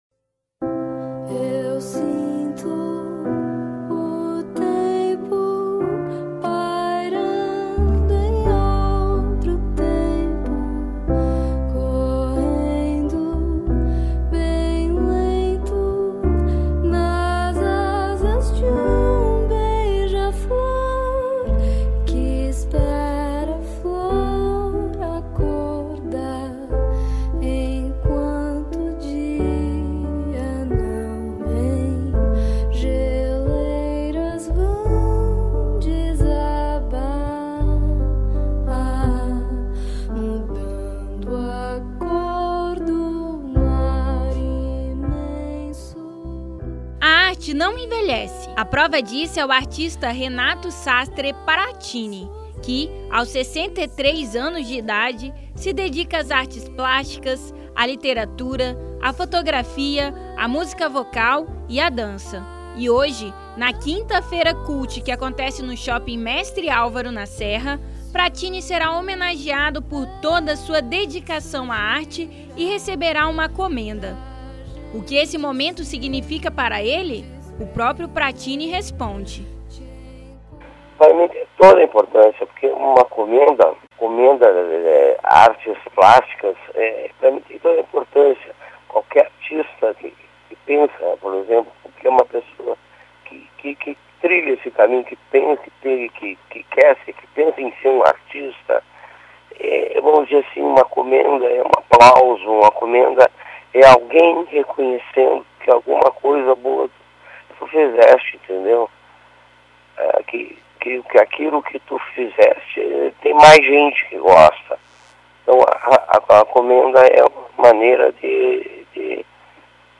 Confira a matéria que foi ao ar no programa Revista Universitária!